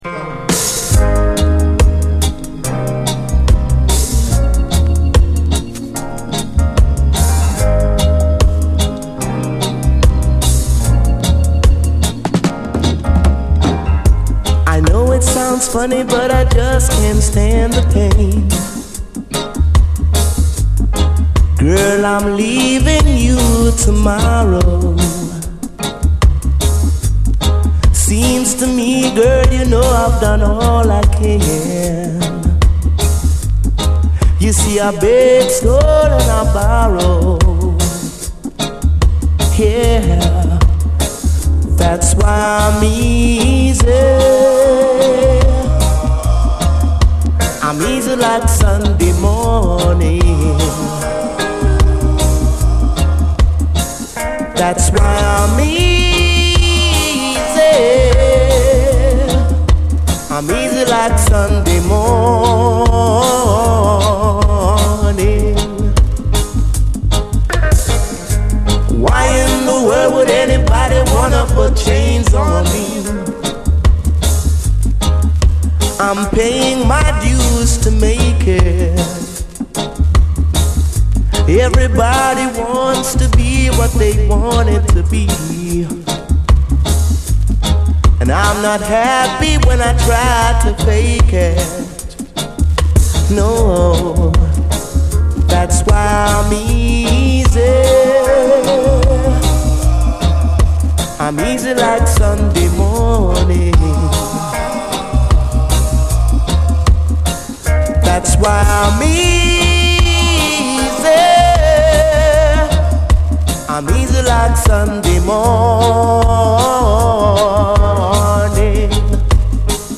エモーショナルに高ぶるUKラヴァーズ名曲！
エモーショナルに高ぶっていく、泣きの一曲！